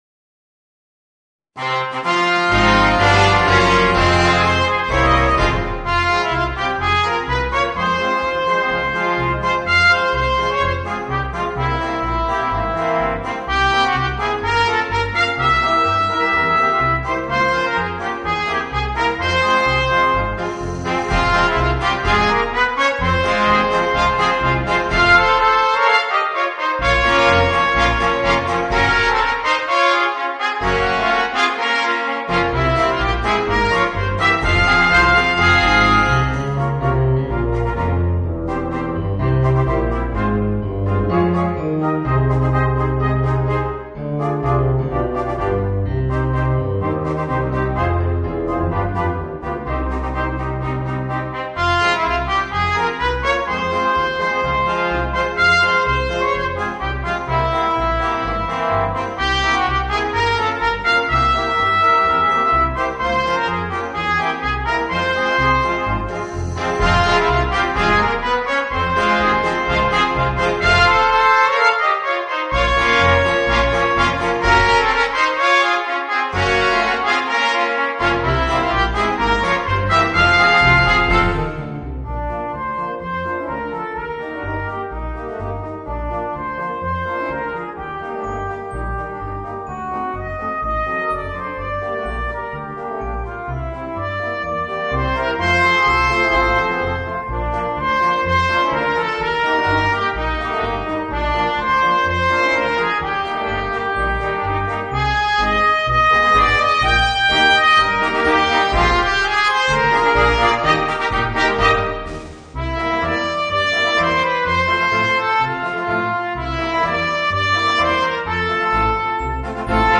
Voicing: 2 Trumpets, Horn, Trombone, Tuba and Drums